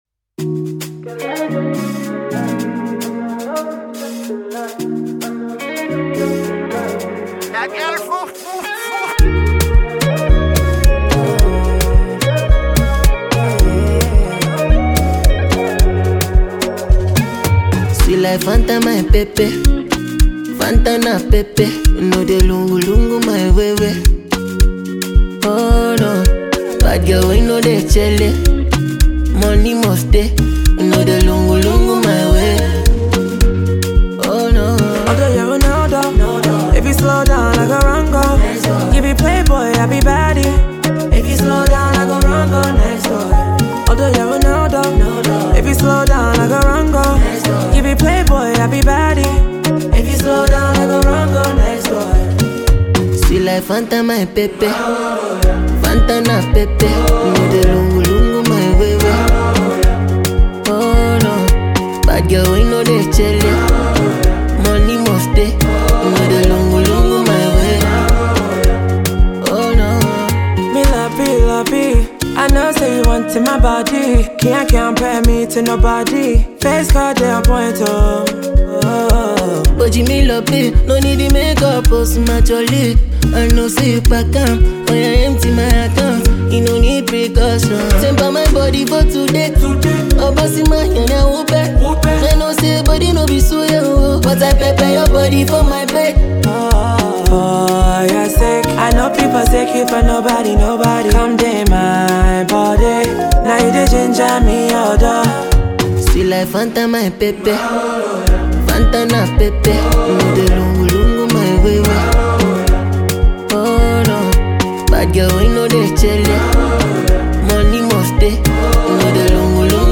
Ghana Music
New tune from Ghanaian female Afro-dancehall artist
Afrobeat